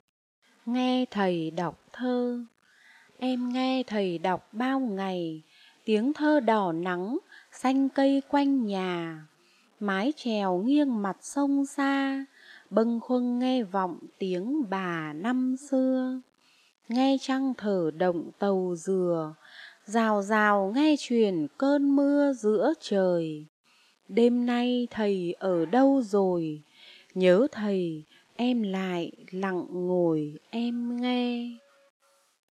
Sách nói | Bài thơ "Nghe thầy đọc thơ"